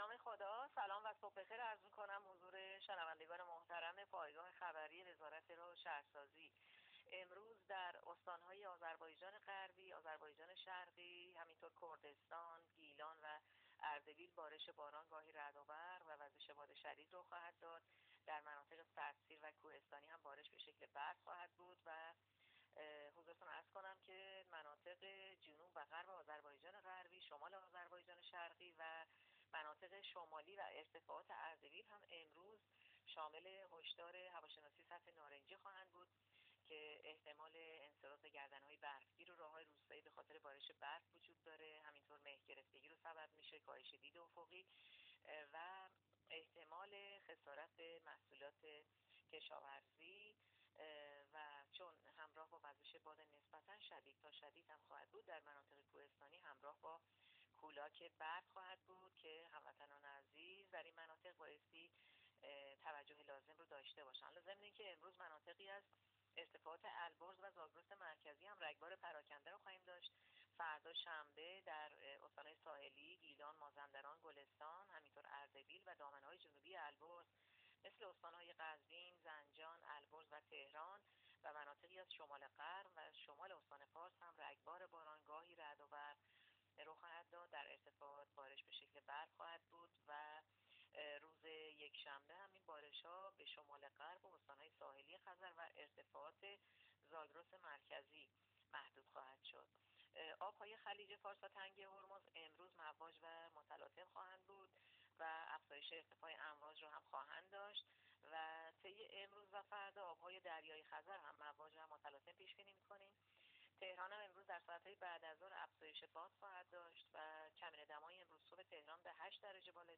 ؛گزارش رادیو اینترنتی از آخرین وضعیت آب و هوای هشتم اسفند؛